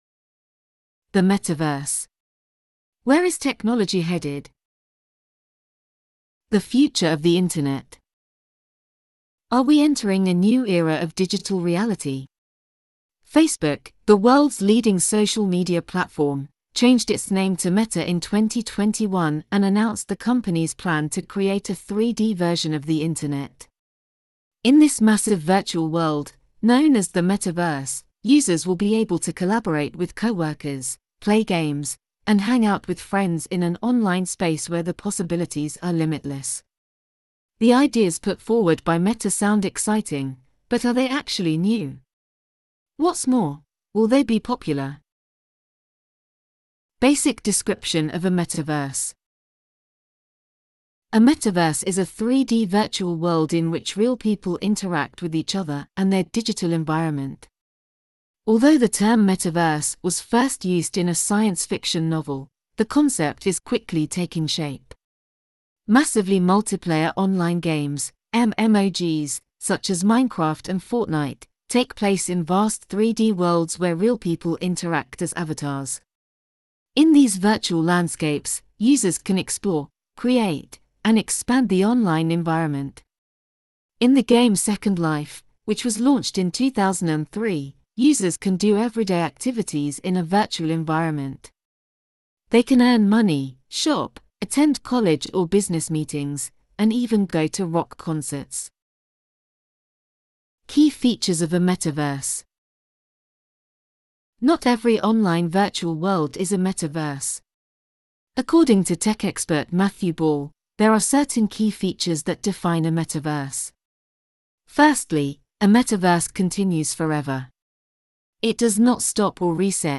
Learn English with this Audio-story and test your knowledge with the English exercises at the end of it.
Difficulty: Intermediate (B1)